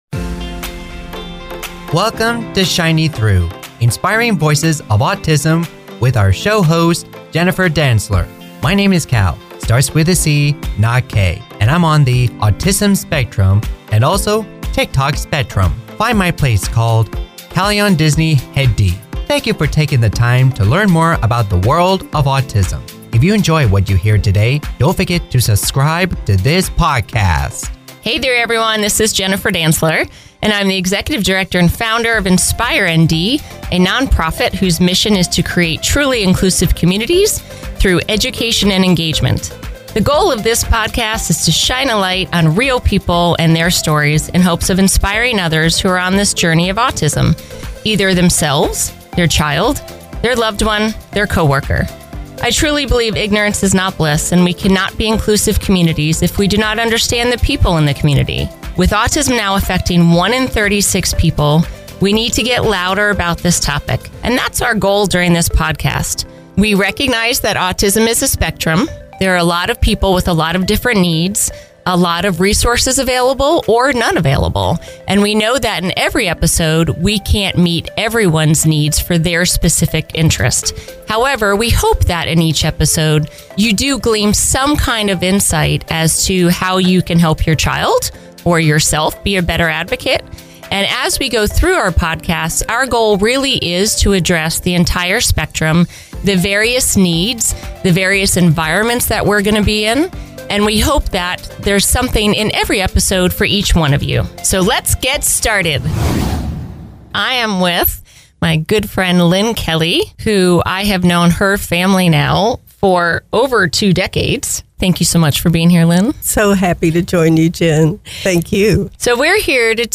From navigating daily life to advocating for inclusion, each episode offers heartfelt conversations, powerful stories, and valuable resources to help us all better understand and support neurodiversity.